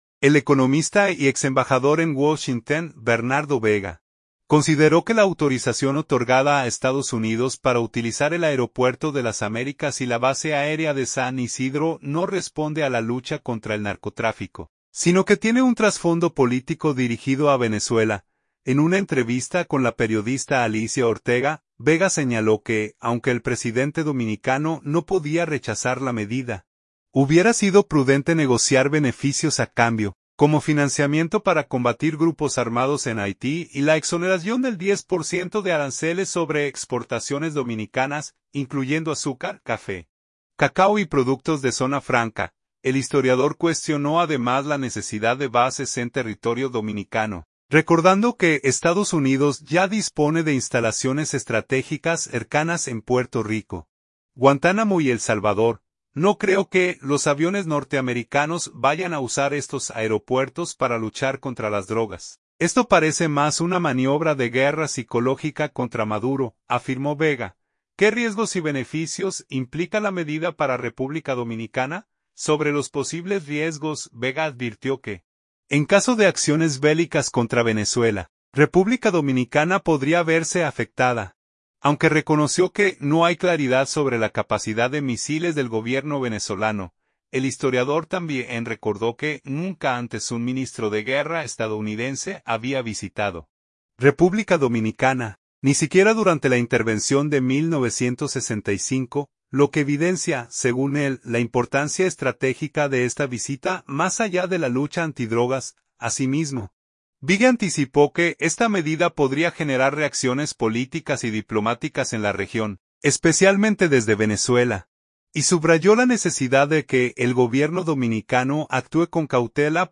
En una entrevista con la periodista Alicia Ortega, Vega señaló que, aunque el presidente dominicano no podía rechazar la medida, hubiera sido prudente negociar beneficios a cambio, como financiamiento para combatir grupos armados en Haití y la exoneración del 10% de aranceles sobre exportaciones dominicanas, incluyendo azúcar, café, cacao y productos de zona franca.